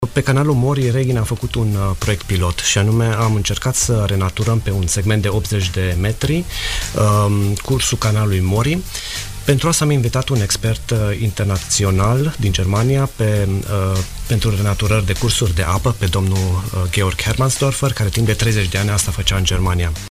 extras din emisiunea Pulsul Zilei